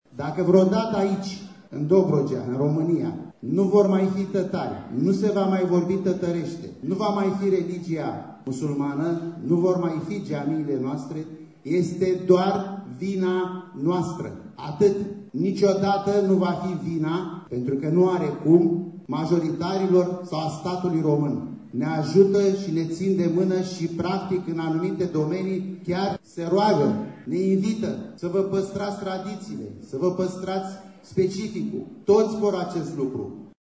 Ziua Limbii Tătare a fost celebrată astăzi prin poezii, cântece și dansuri tradiționale tătărești. Evenimentul a fost organizat la Centrul Cultural “Jean Constantin” din municipiul Constanța, la câteva zile după data oficială, 5 mai, stabilită în anul 2010.